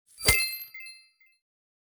Coin Bag Reward.wav